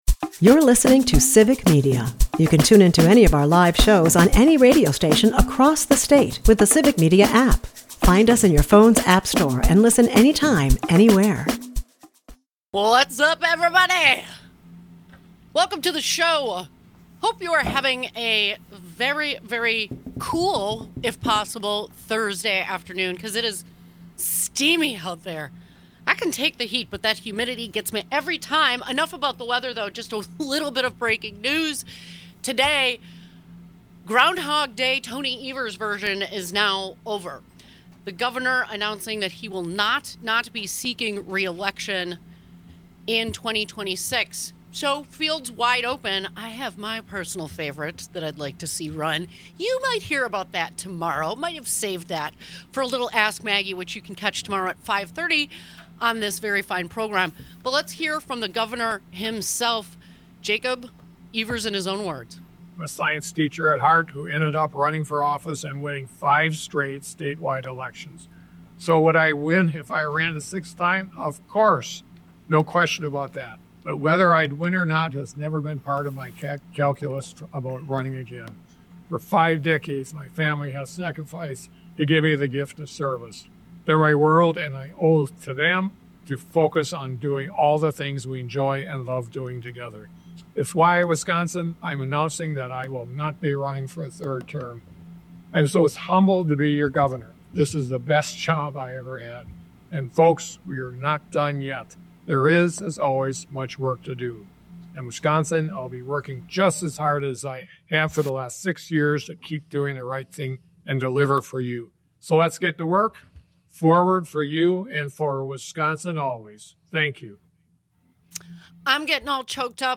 unpacks the high-stakes moment with expert guests